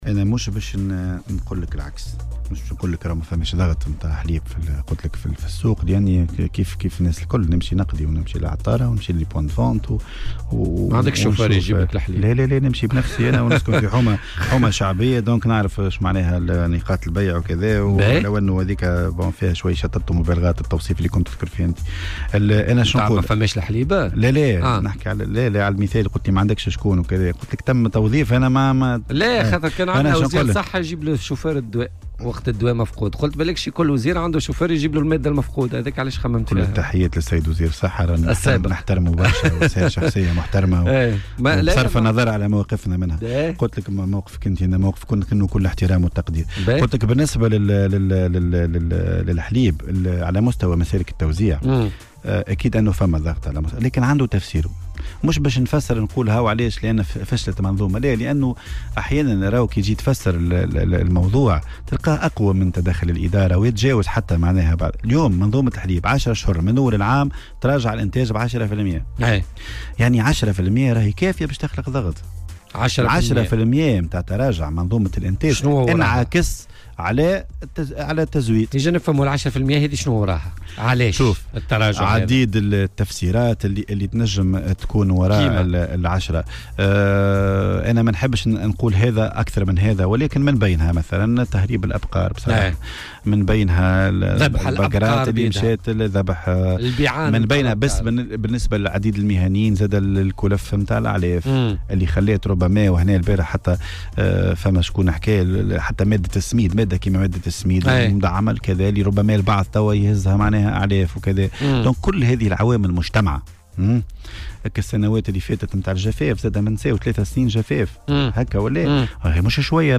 وقال ضيف "بوليتيكا" على "الجوهرة أف أم" ان موسم انتاج الحليب اقترب من فترة الذروة مما سيؤدي إلى تحقيق انفراج في أزمة الحليب خلال أيام.